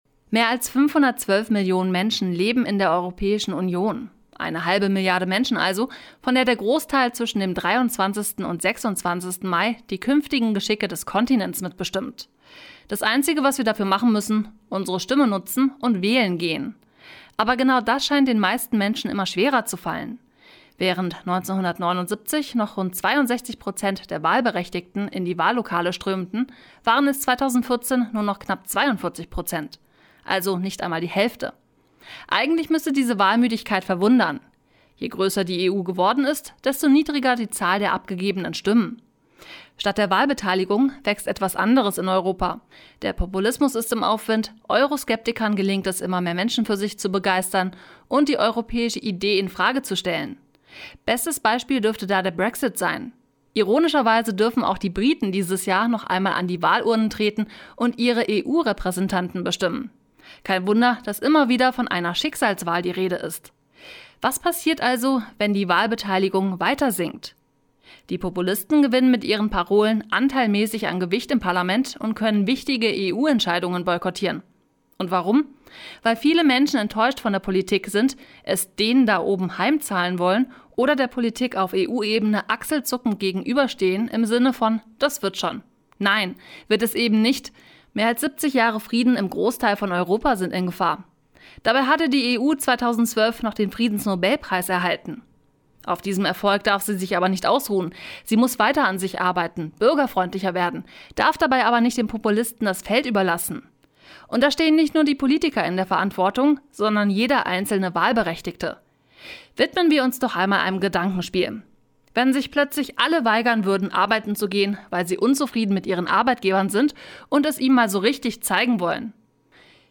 Sendung: Mittendrin Redaktion Kommentar